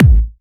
Bassdrums
ED Bassdrums 34.wav